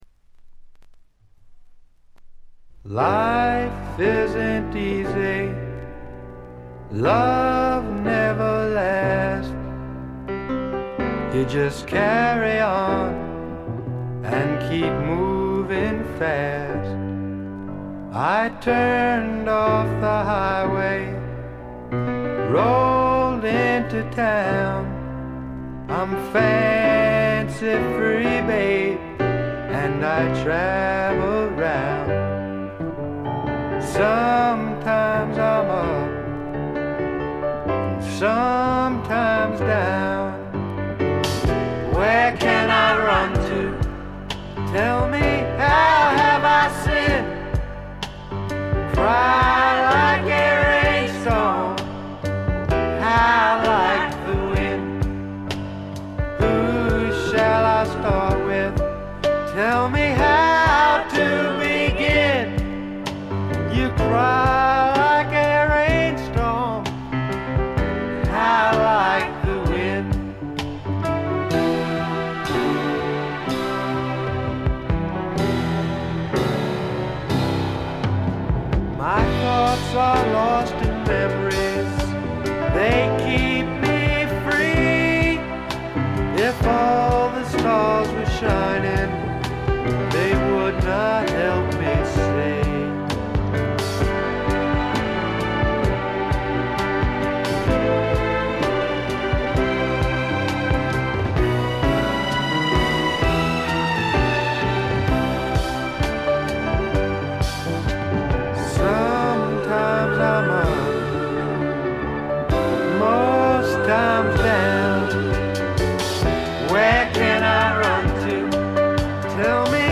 ほとんどノイズ感無し。
素晴らしい楽曲と素朴なヴォーカル。フォーキーな曲から軽いスワンプ風味を漂わせる曲までよく練られたアレンジもよいです。
試聴曲は現品からの取り込み音源です。
Vocals, Piano, Harmonica, Acoustic Guitar